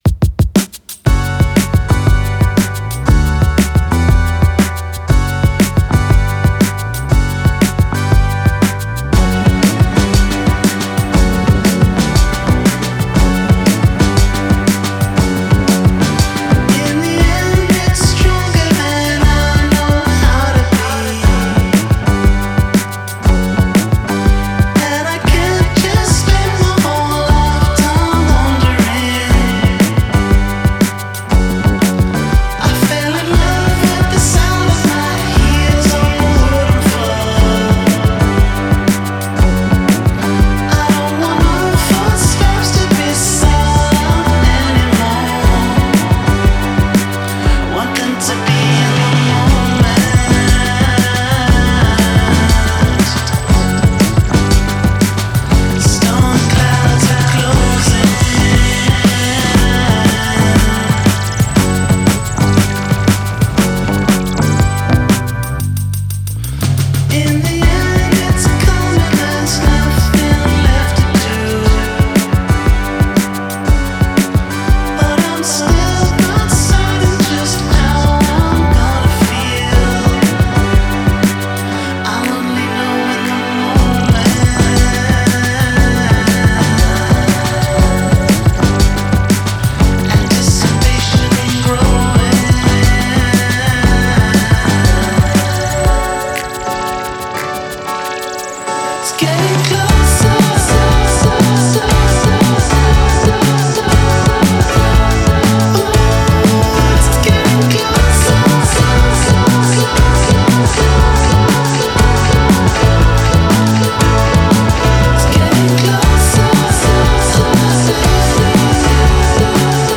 Genre: Indie Rock, Psychedelic